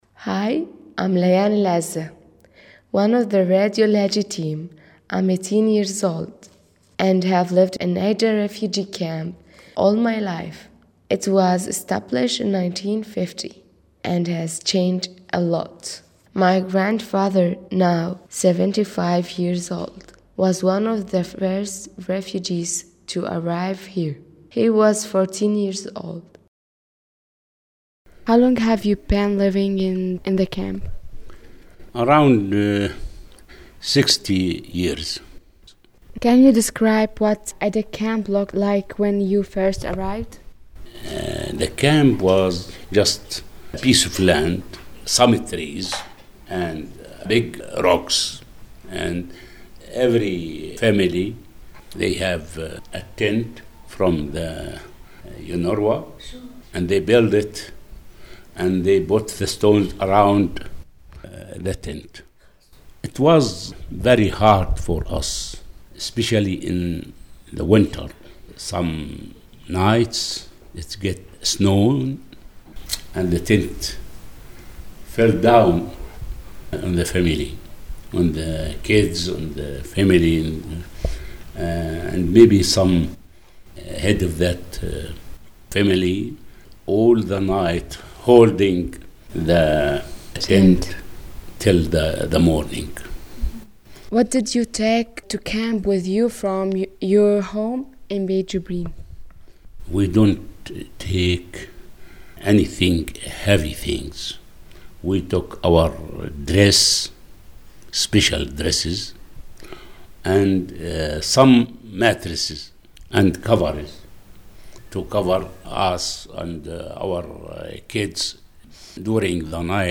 In the following podcast you will hear interviews by two Palestinian refugees telling their personal story of how they were excluded from their village, became refugees and ended up in Aida Camp.